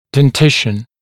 [den’tɪʃn] [дэн’тишн] верхний и нижний зубные ряды в совокупности; прикус